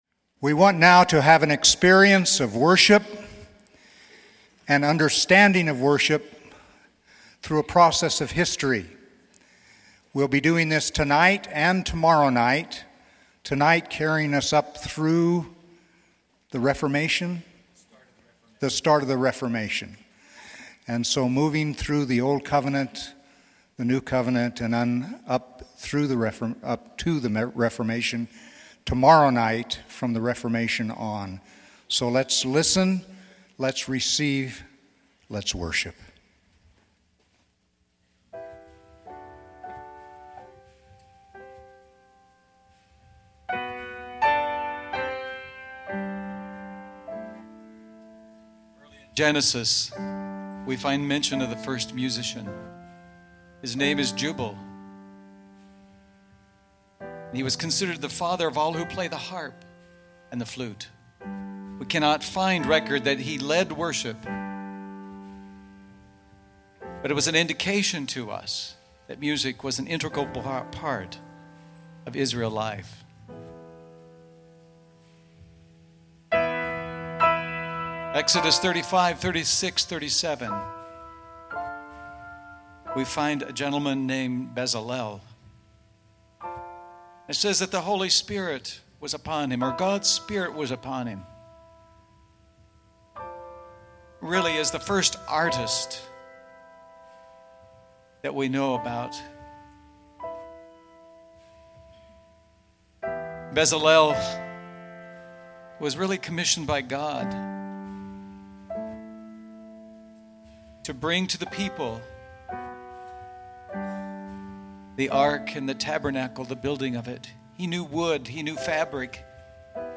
Recorded at the Renovaré International Conference in Denver, CO.